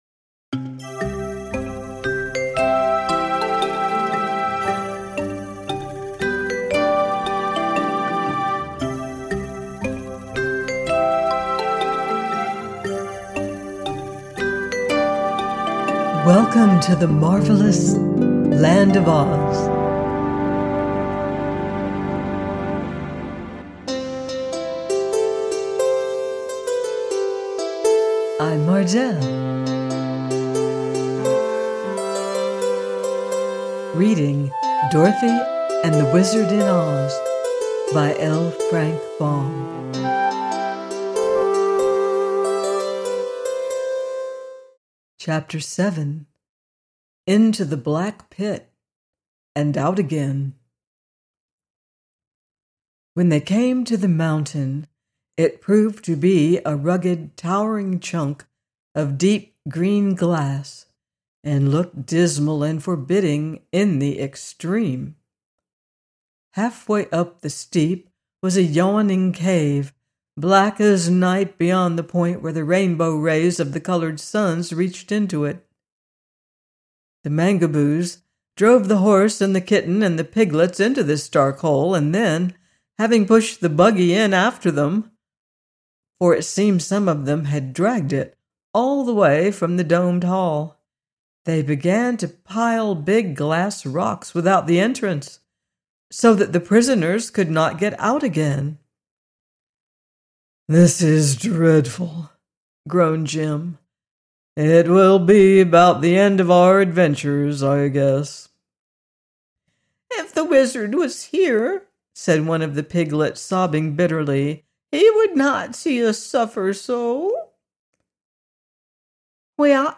DOROTHY AND THE WIZARD IN OZ: by L. Frank Baum - audiobook